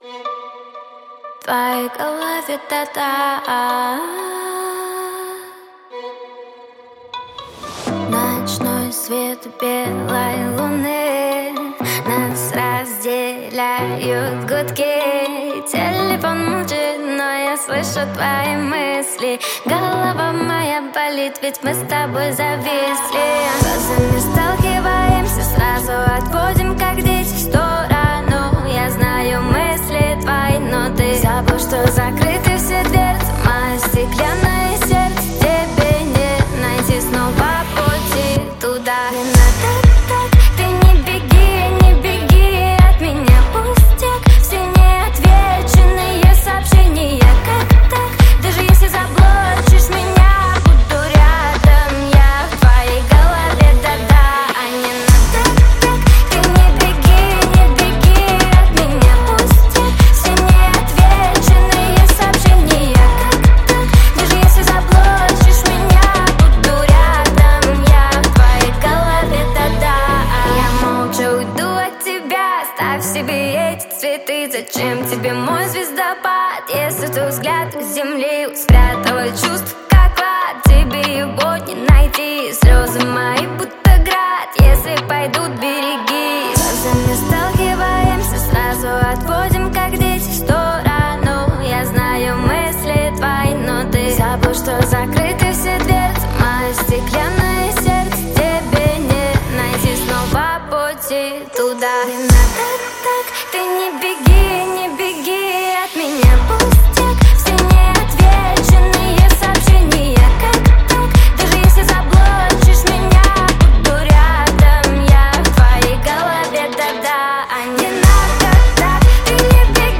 Хип-хоп